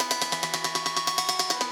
Index of /musicradar/shimmer-and-sparkle-samples/140bpm
SaS_Arp01_140-E.wav